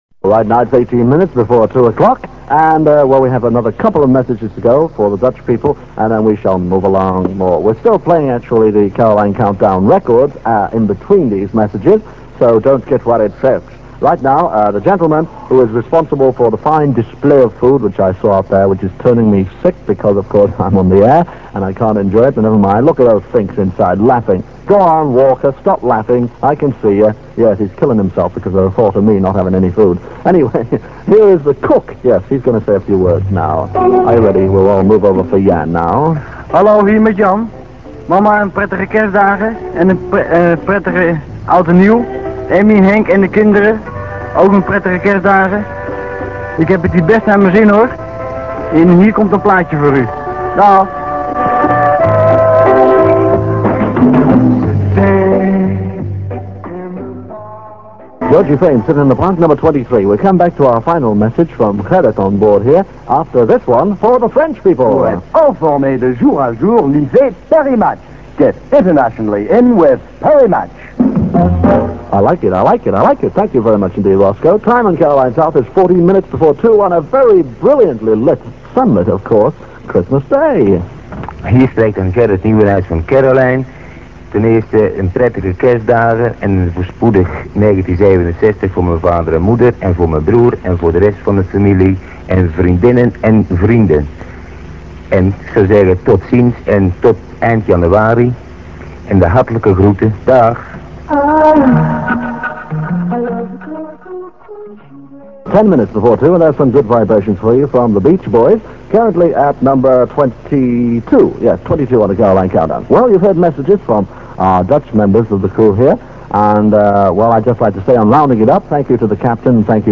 click to hear audio Dave Lee Travis on the Christmas Day 1966 edition of The Caroline Countdown with some of the Dutch crew-members on Caroline South.